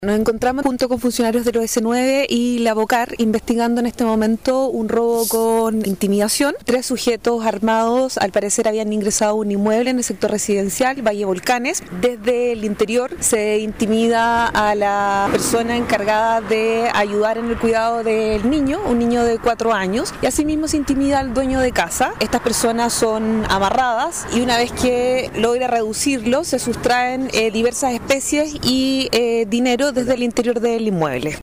La Fiscal adjunta de Puerto Montt Natalie Johnson, detalló que efectivos del OS-9, y Labocar, de la Sección de Investigación Criminal de Carabineros, están realizando diversas pesquisas para dar con la ubicación y captura de los asaltantes.